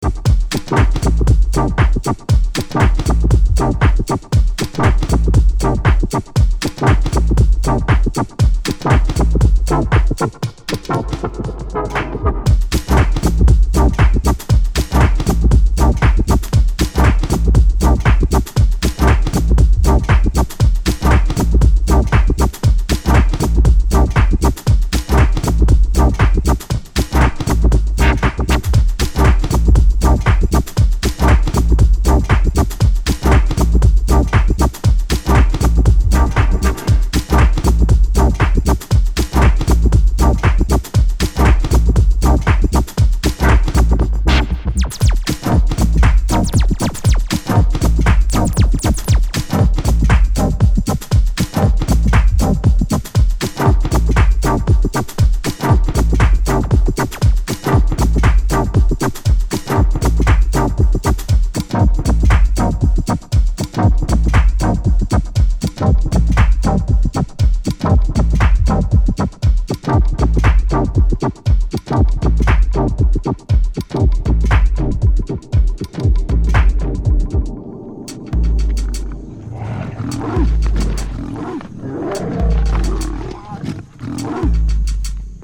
Modern sample house